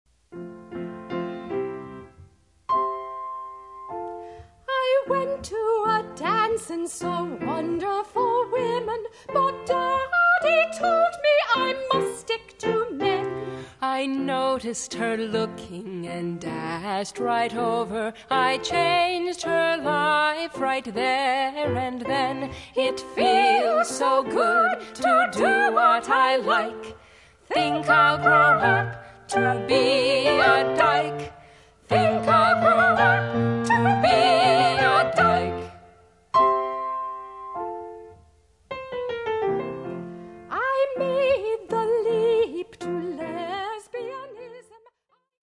--comedy music parody